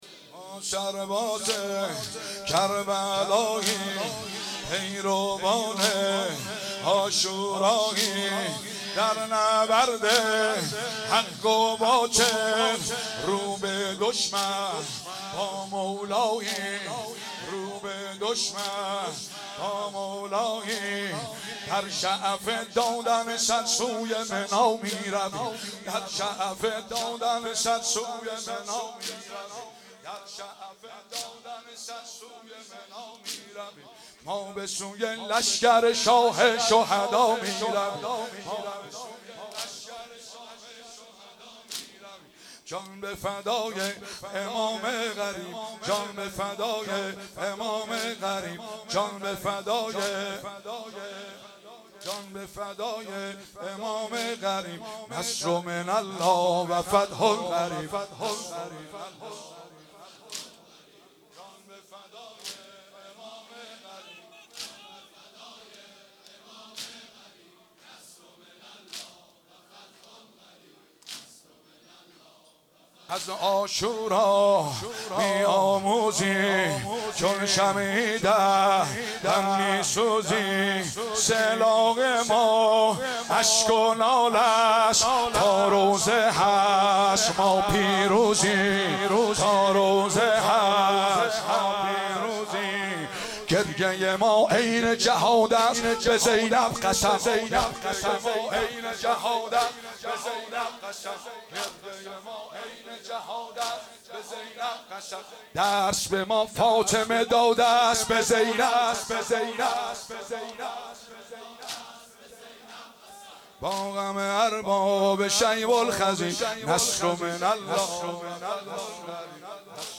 شب اول محرم 96 - هیئت فاطمیون - واحد- ما سرباز کربلاییم
محرم 96